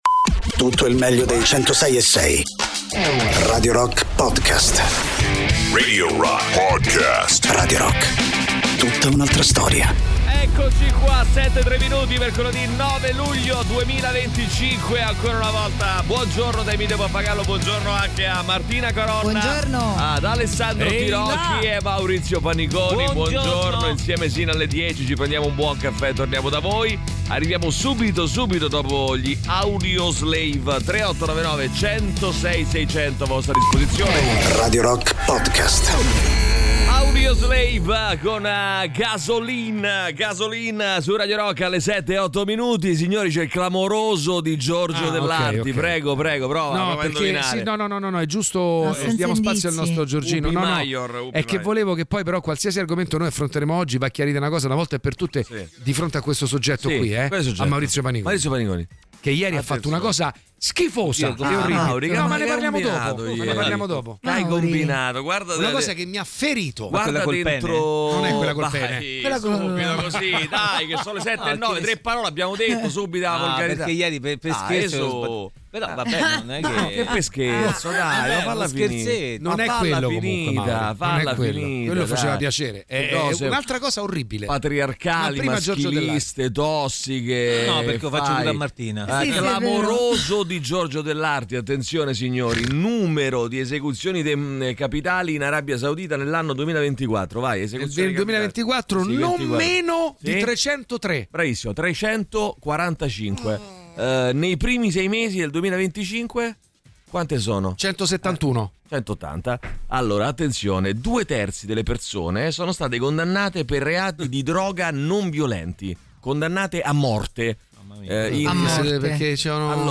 in diretta dal lunedì al venerdì, dalle 17 alle 20